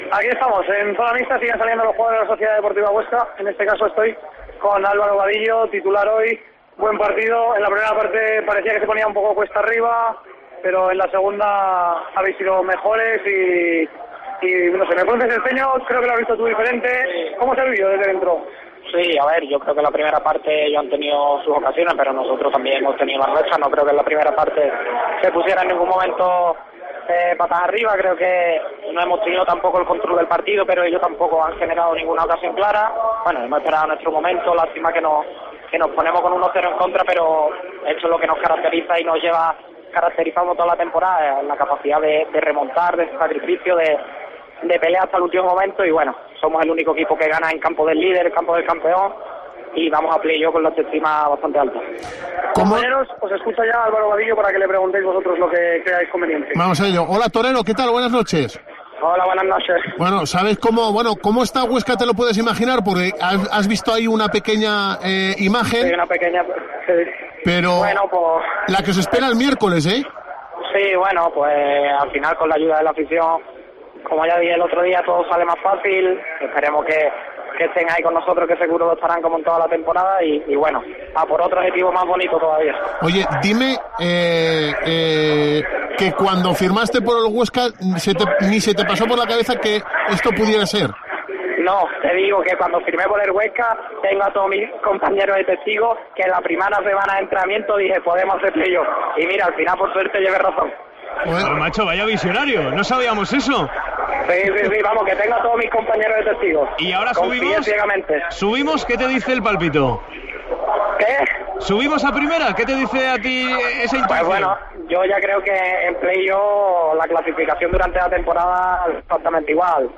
Nos atiende el extremo azulgrana tras el Levante 1-2 Huesca.
Vadillo atiende a COPE tras el Levante 1-2 Huesca